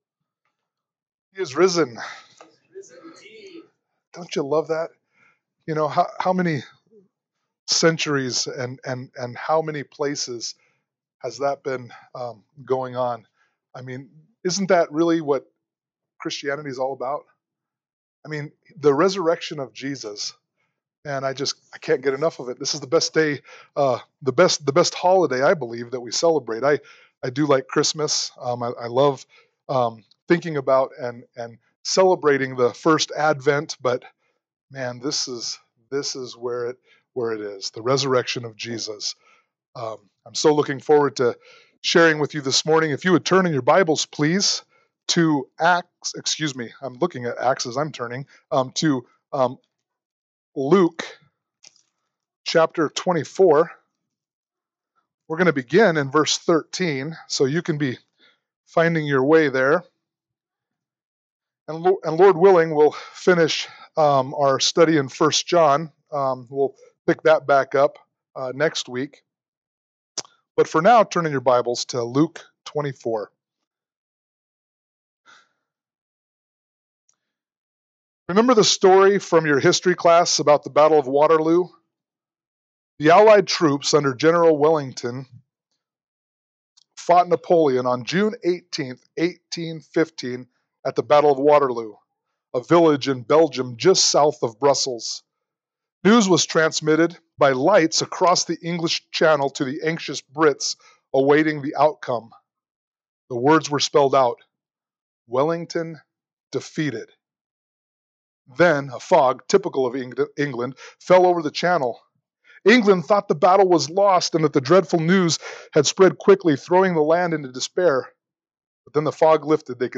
Luke 24:13-35 Service Type: Sunday Morning Worship « Easter SonRise Service 1 John 5:13-17